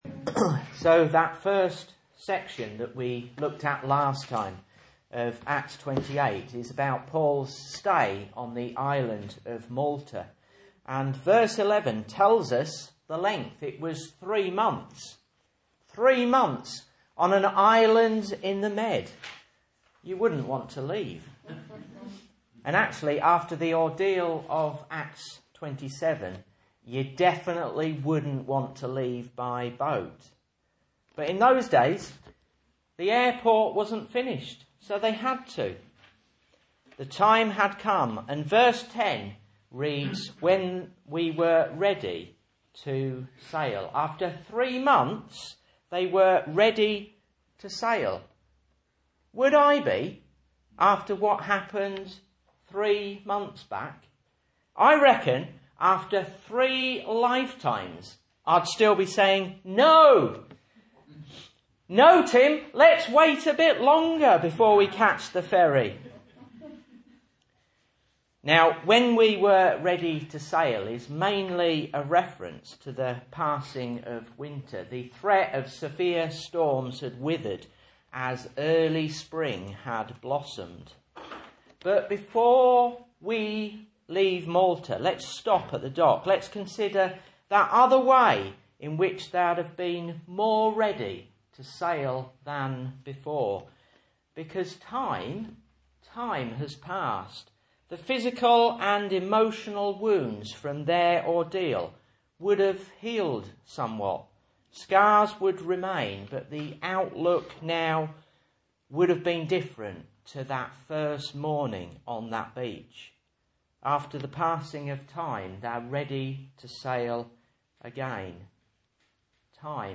Message Scripture: Acts 28:11-16 | Listen